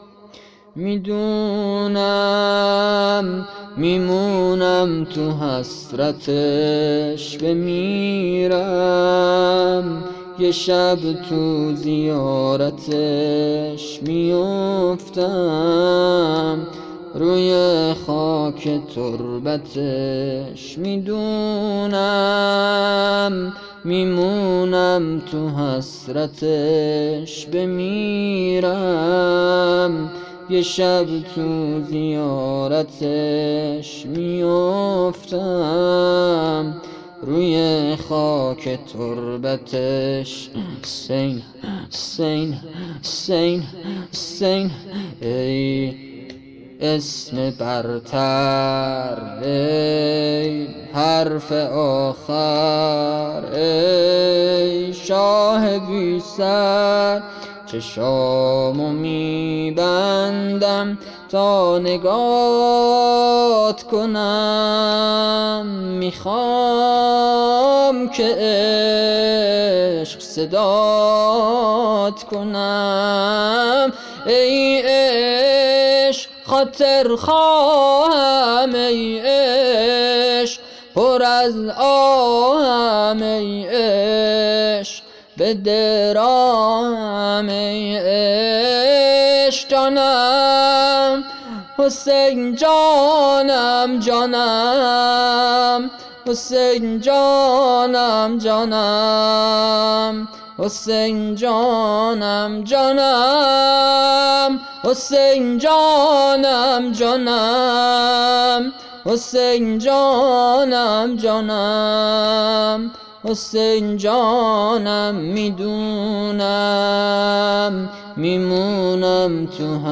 زمینه احساسی